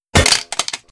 Weapon_Drop 01.wav